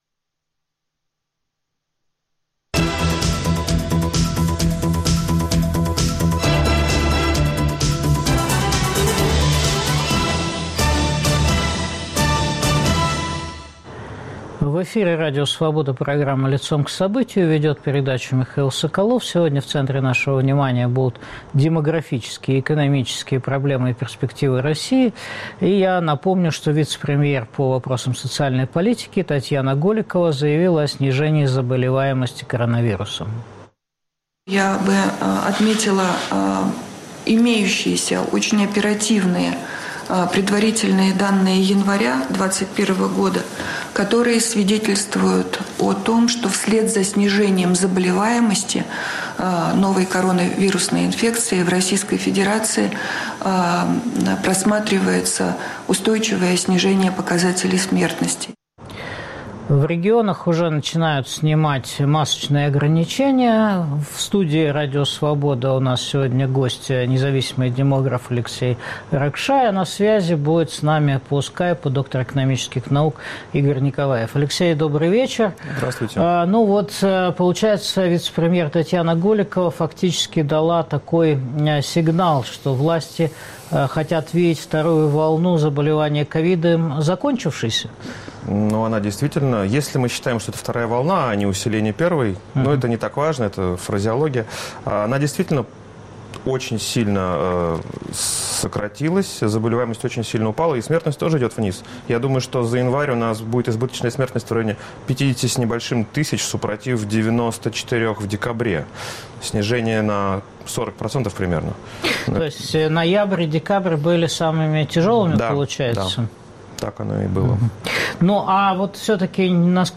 Россия - в лидерах по смертности от COVID-19? Ждать ли 500 млрд. выплат от Путина к выборам? Обсуждают экономист